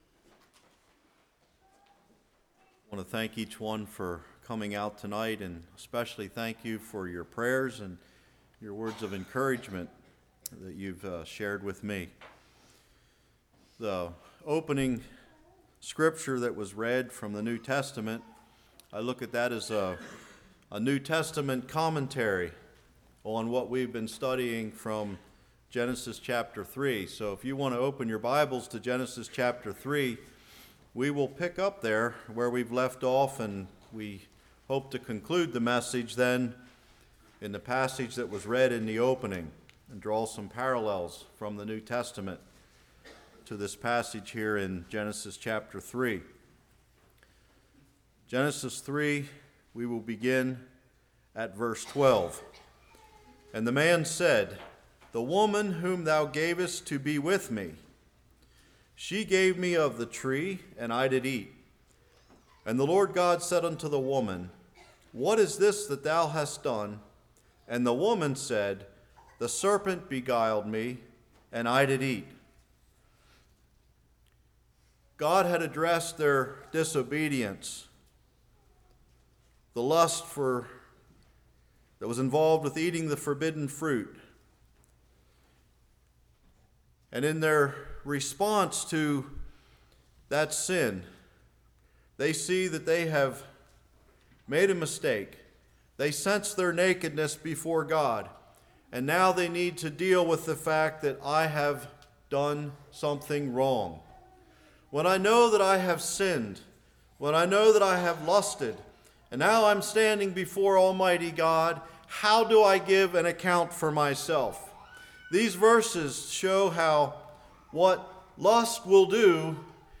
1 John 3:8-12 Service Type: Evening Pass Blame Lost Leadership Angry Children « Overcoming Fear Foundations for Moral Purity